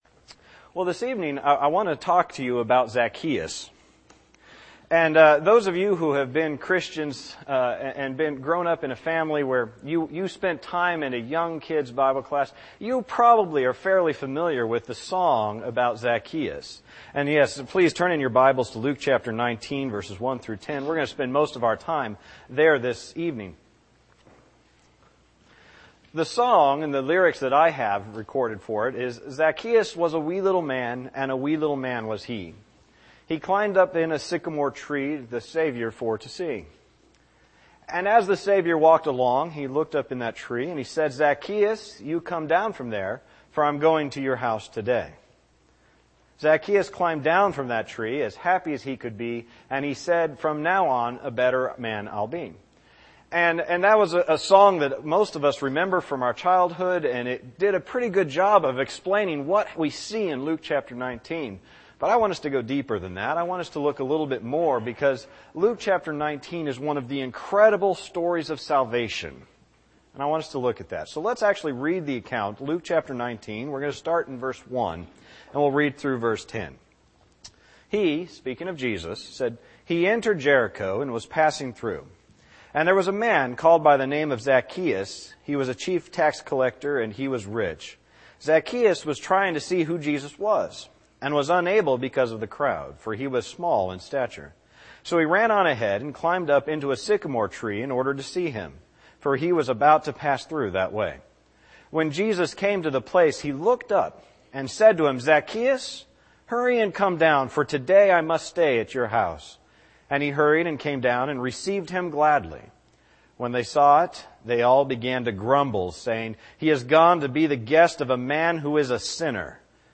Service: Sun PM Type: Sermon